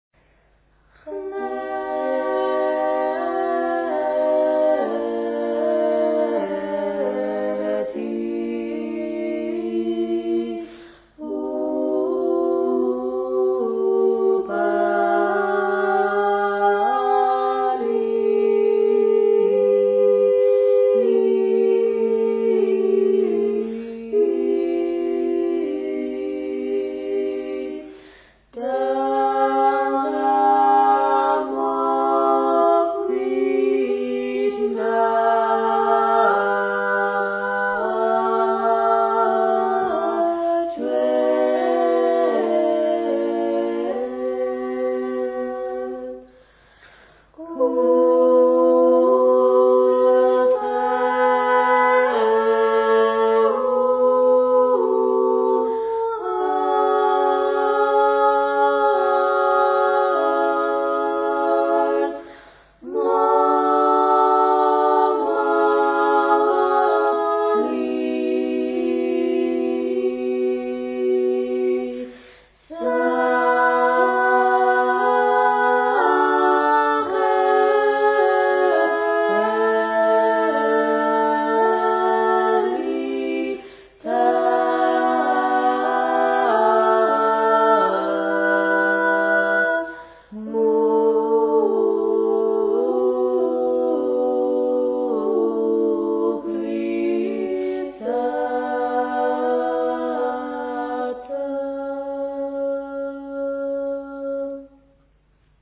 especially as she sings the p'irveli khma (top, or first, voice). The second time we all sat down together, we experimented with seating and microphone placement until a better mix was achieved.
The years of singing together are audible in their voices; the song I've included here is a very well-known sacred song from Kakheti region,